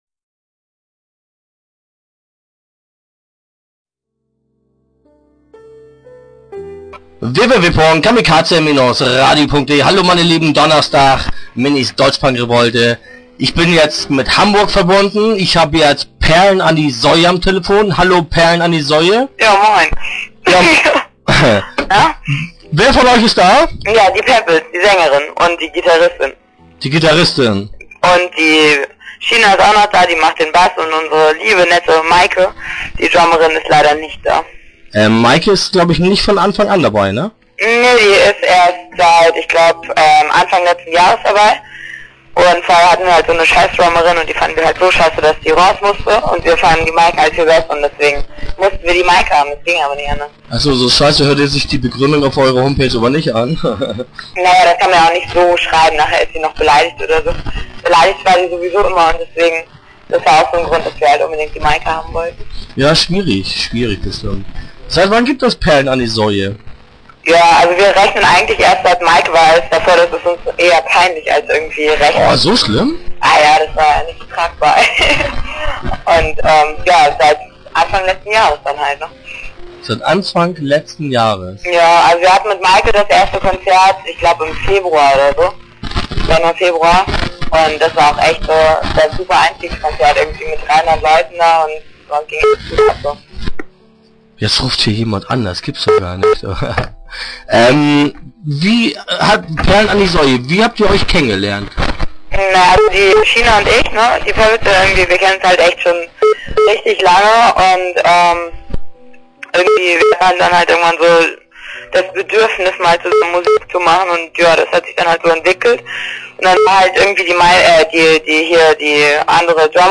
Start » Interviews » Perlen an die Säue